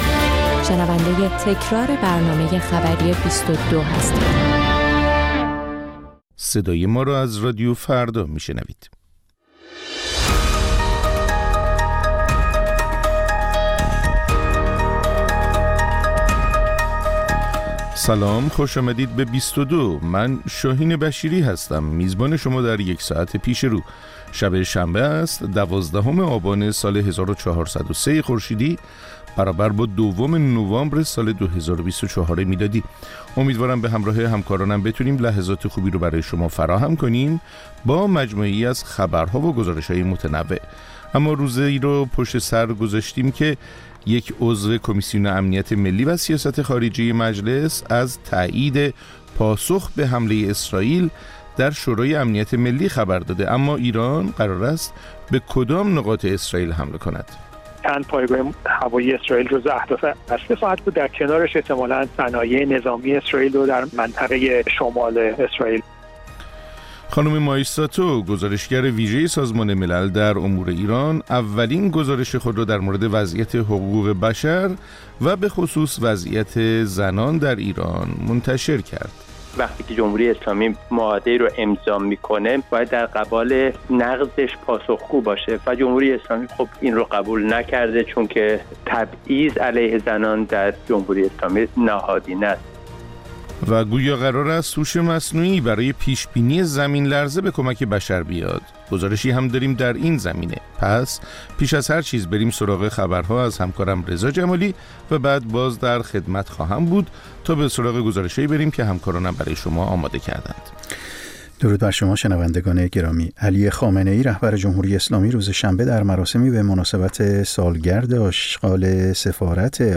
بازپخش برنامه خبری ۲۲